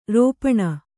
♪ rōpaṇa